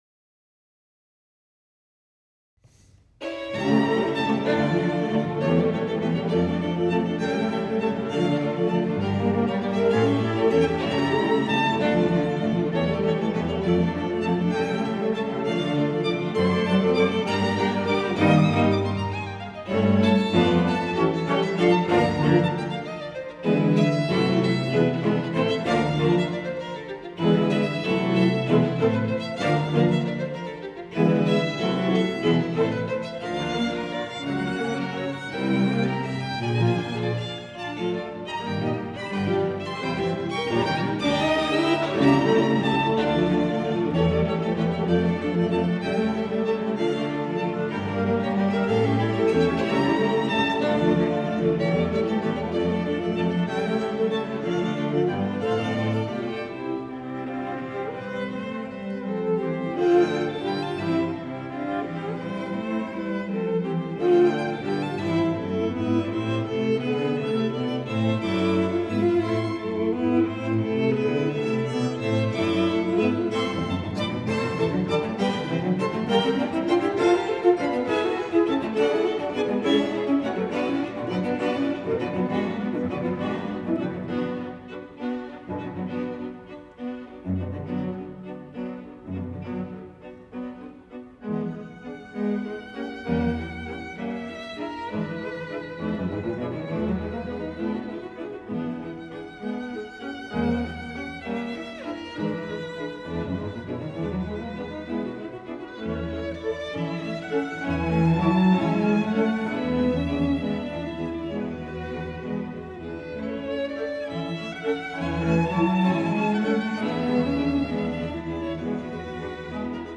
live in Japan, Tokyo /Fuchu, Wien Hall
Streichsextett No. 1